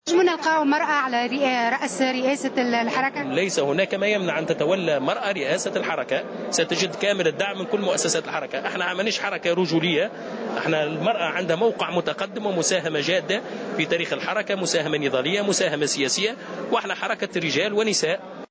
قال القيادي في حركة النهضة، رفيق عبد السلام اليوم في تصريح لمراسلة "الجوهرة أف أم" إنه ليس هناك ما يمنع تولي امرأة لرئاسة حركة النهضة.